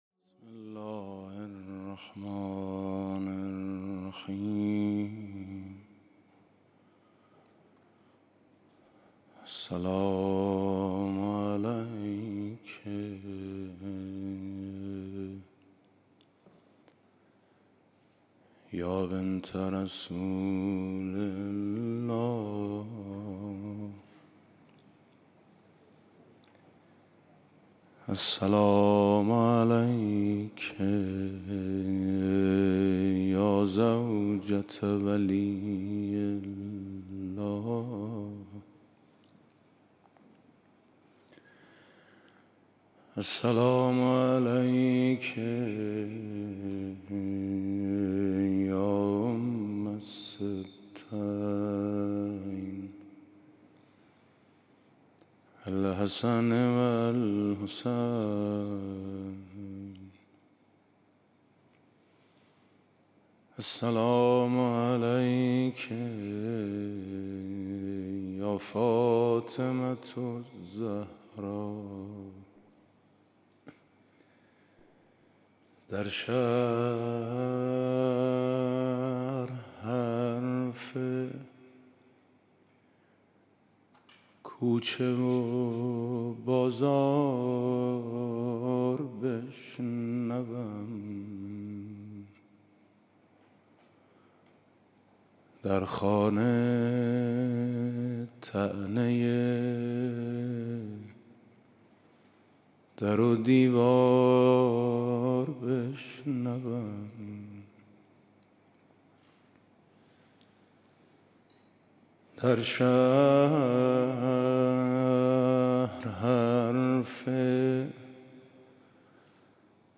دومین شب مراسم عزاداری شهادت حضرت فاطمه زهرا سلام‌الله‌علیها
مداحی آقای میثم مطیعی